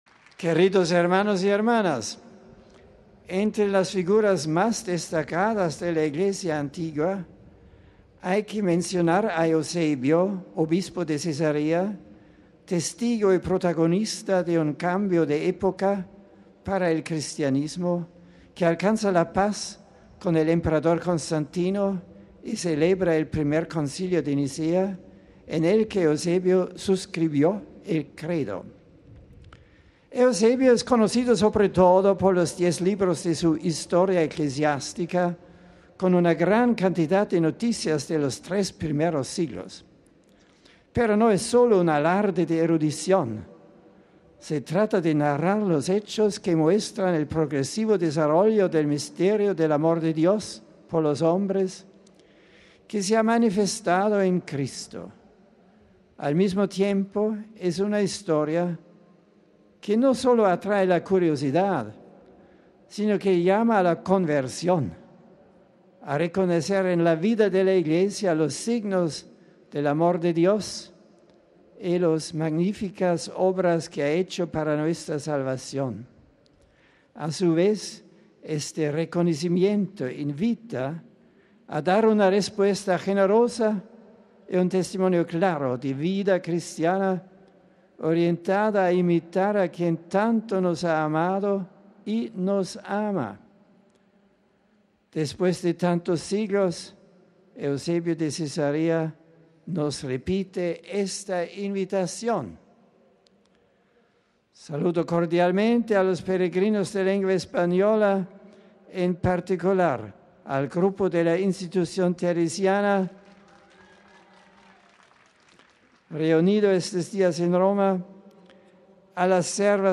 Miércoles, 13 jun (RV).- El Papa, ante más de 30.000 fieles, ha dedicado esta mañana su catequesis a la figura de Eusebio, obispo de Cesarea, primer historiador de la Iglesia que vivió entre el 260 y el 337 después de Cristo.
Este ha sido el resumen que de su catequesis ha hecho el Santo Padre en español para los peregrinos de nuestra lengua presentes en la Plaza de San Pedro: RealAudio